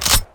Shotgun_PumpEnd 01.wav